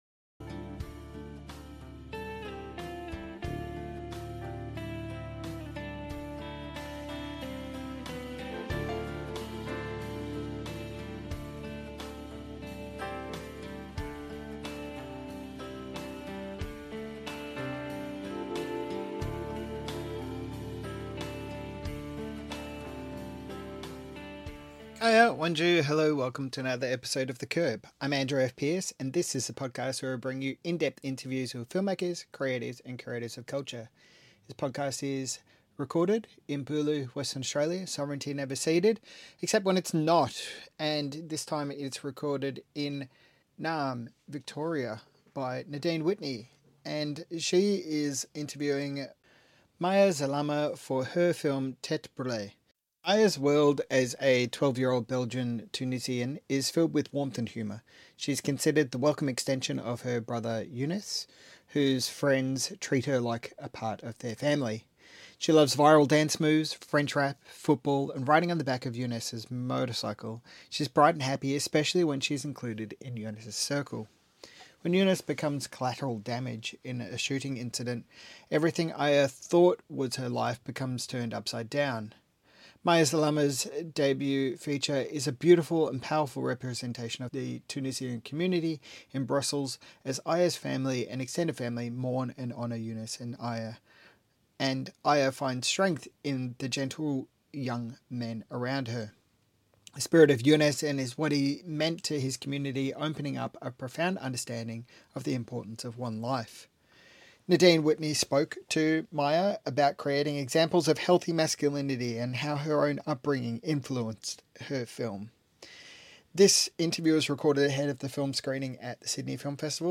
MIFF Interview